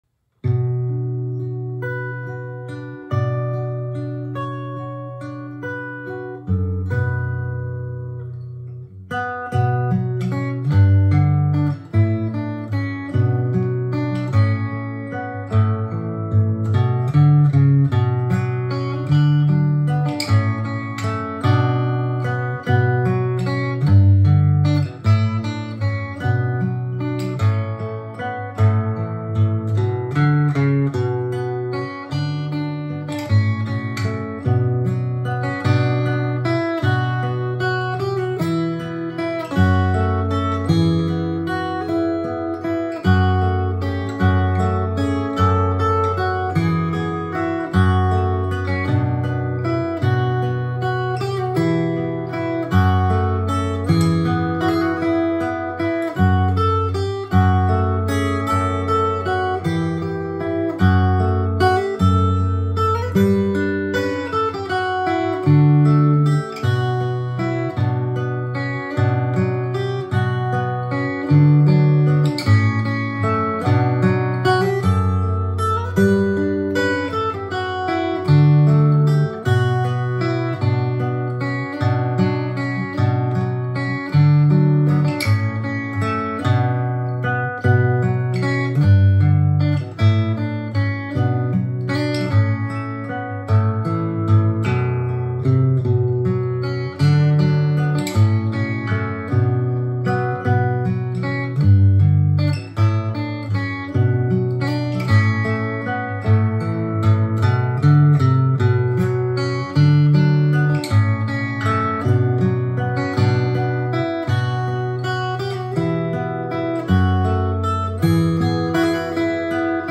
Agni-Parthene-Guitar-cover-عذراء-يا-أم-الإله-غيتار.mp3